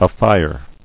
[a·fire]